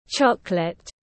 Chocolate /ˈtʃɒk.lət/